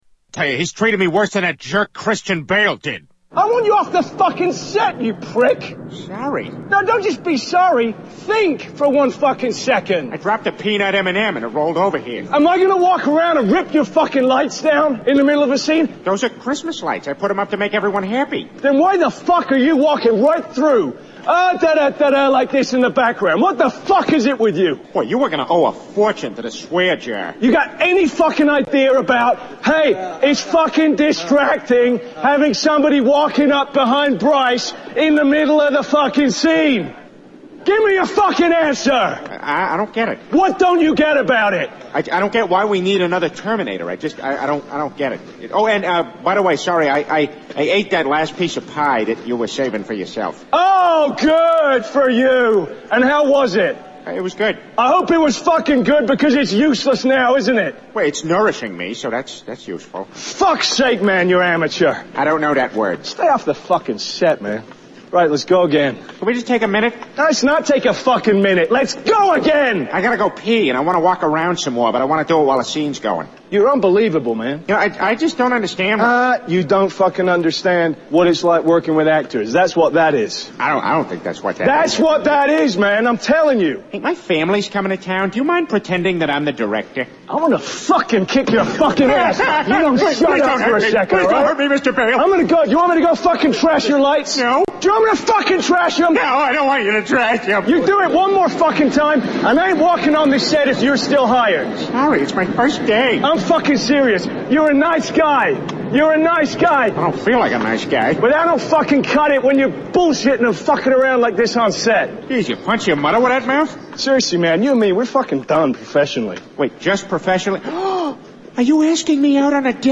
Tags: Soundboards Talk to each other Mash up Mash up clips Mash up sounds Soundboard talking